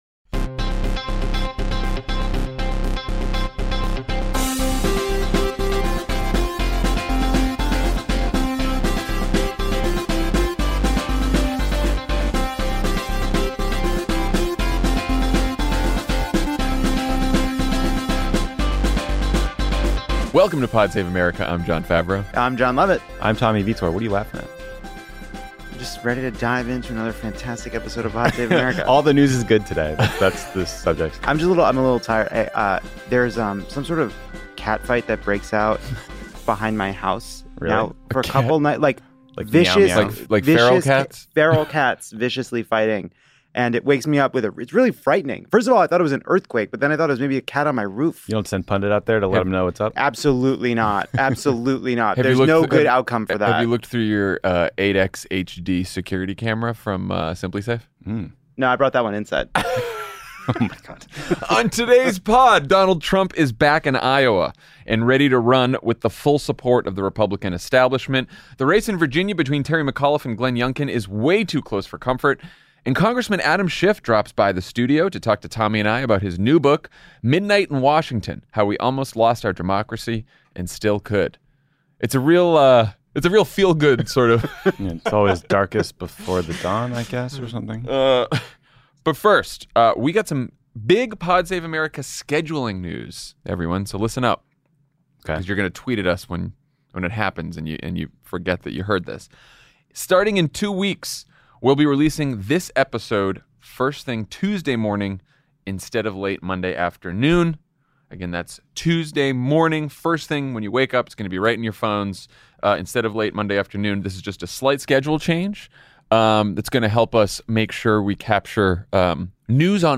Donald Trump returns to Iowa and teases a second run with the full support of the Republican establishment, the race in Virginia between former Governor Terry McAuliffe and Trump-backed private equity CEO Glenn Youngkin is way too close for comfort, and Congressman Adam Schiff (D-CA) drops by the studio to talk to Tommy Vietor and Jon Favreau about his new book, Midnight in Washington: How We Almost Lost Our Democracy and Still Could.”